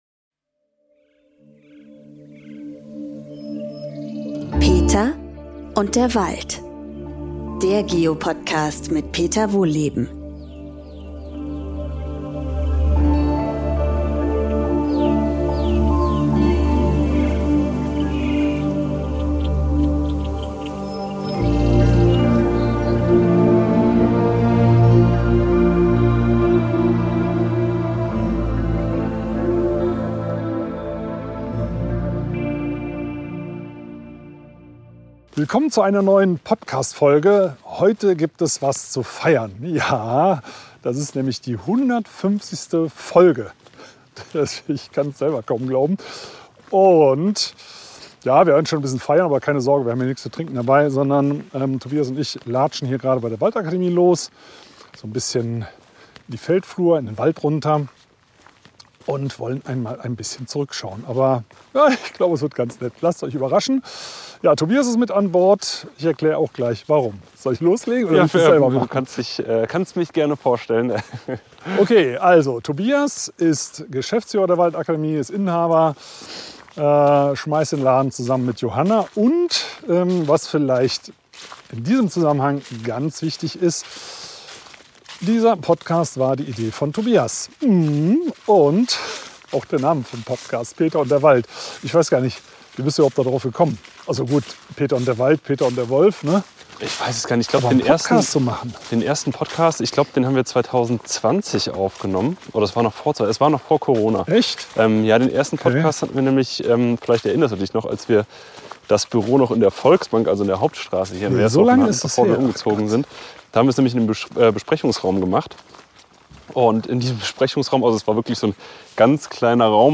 Gemeinsam schwelgen sie bei einem Waldspaziergang in Erinnerungen über die ersten Schritte des Podcasts und die wildesten Geschichten.